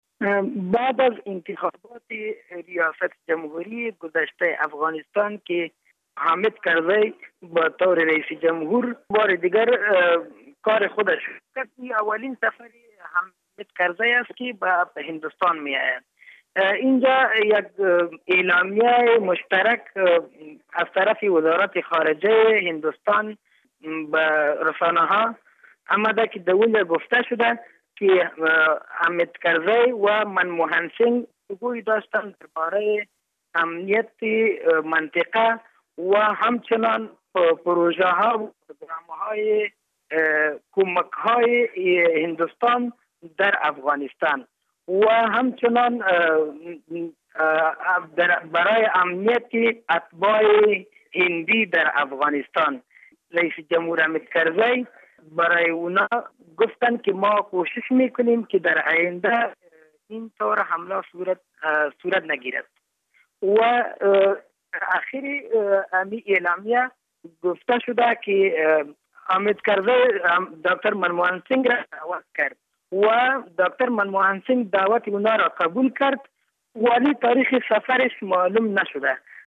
اظهارات یک ژورنالیست افغان مقیم هند در مورد سفر رییس جمهور کرزی به آن کشور